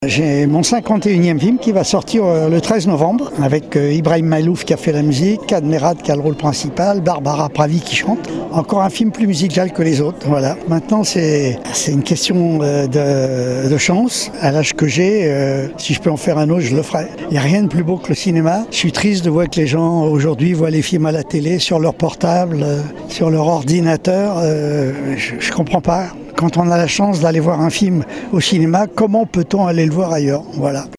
Au micro d’ODS Radio, Claude Lelouch partage sa vision du cinéma et l’importance de ne jamais cesser de raconter des histoires tant que l’énergie et l’envie sont là.